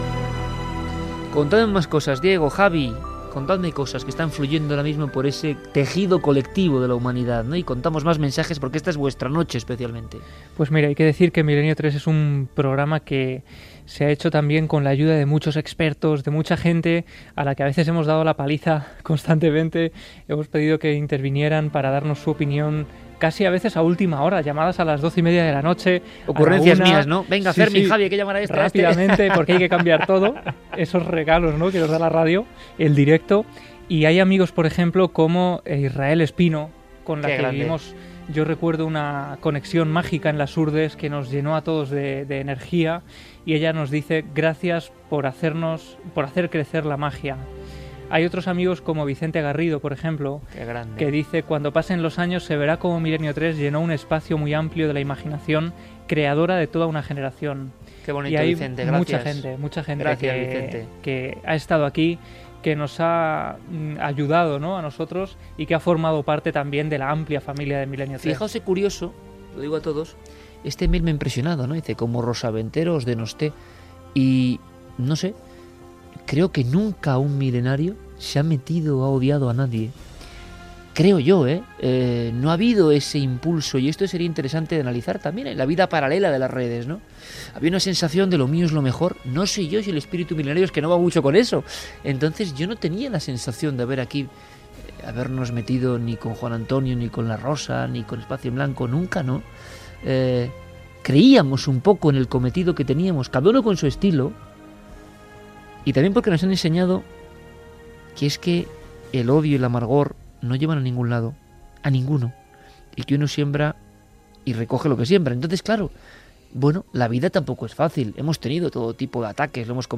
0aa0a68b7bae8a37cb47ae39137fc60490db545e.mp3 Títol Cadena SER Emissora Ràdio Barcelona Cadena SER Titularitat Privada estatal Nom programa Milenio 3 Descripció Última edició del programa.
Divulgació